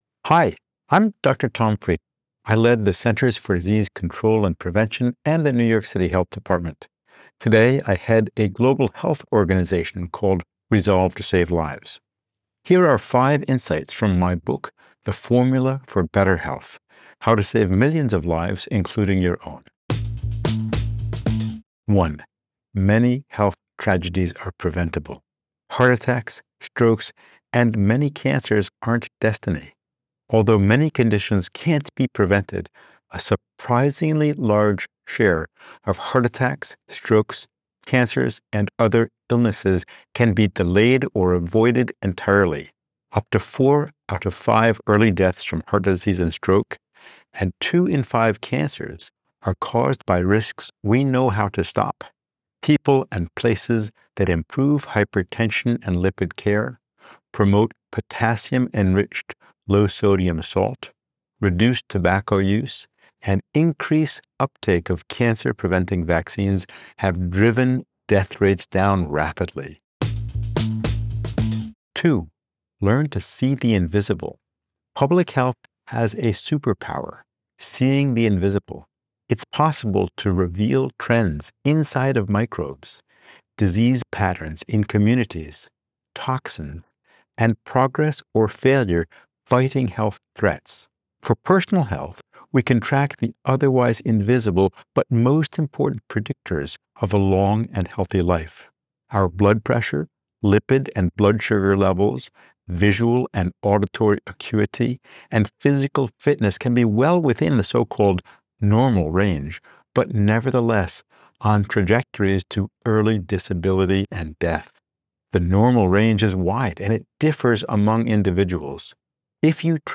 Listen to the audio version of this Book Bite—read by Tom himself—below, or in the Next Big Idea App.